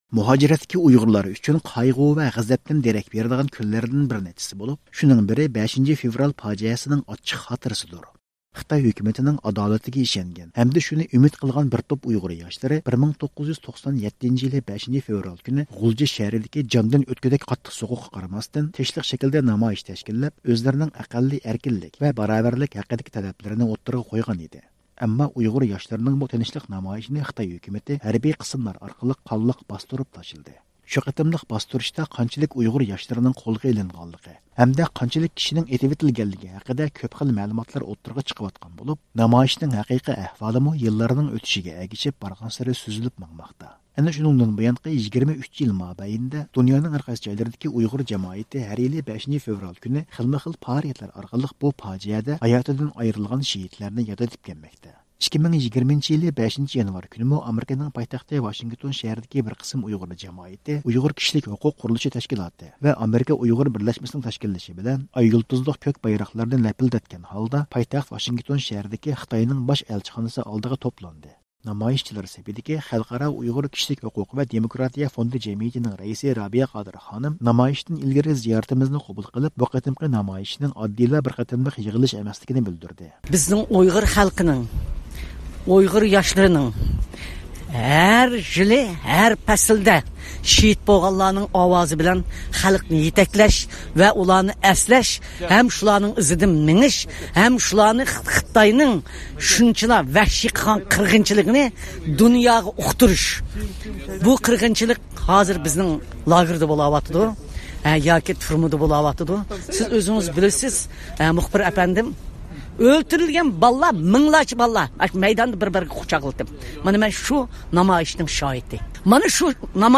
خىتاي ئەلچىخانىسى ئالدىدا نامايىشۋاشىنگتون شەھرىدىكى خىتاي ئەلچىخانىسى ئالدىدا ئۆتكۈزۈلگەن «5-فېۋرال پاجىئەسى»نىڭ 23 يىللىقىنى خاتىرىلەش نامايىشى، 2020-يىلى 5-فېۋرال، ۋاشىنگتون شەھرى
شۇنىڭدىن كېيىن  نامايىشچىلار «ئۇيغۇرلارغا ئەركىنلىك!»، «خىتاي، شەرقىي تۈركىستاندىن يوقال!» دېگەندەك شوئارلارنى ئۈنلۈك توۋلاپ ئۇيغۇرلارنىڭ يۈرەك سۆزلىرىنى ھەمدە ئۇلارنىڭ يانماس ئىرادىسىنى نامايەن قىلدى.